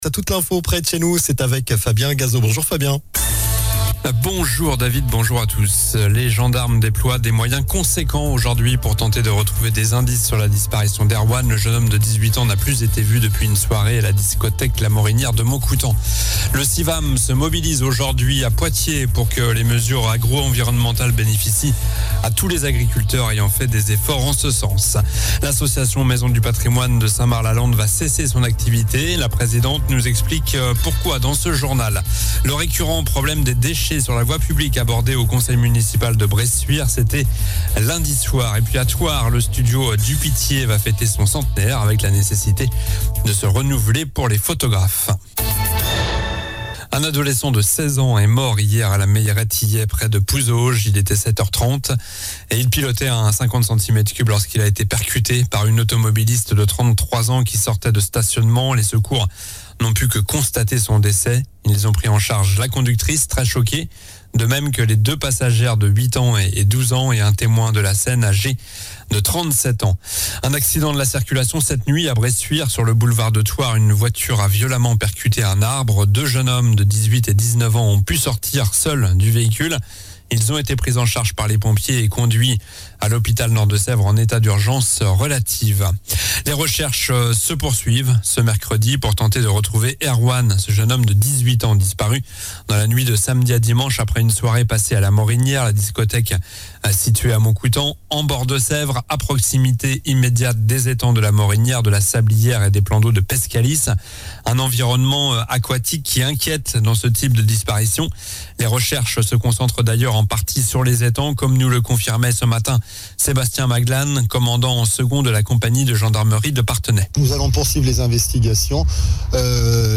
Journal du mercredi 14 février (midi)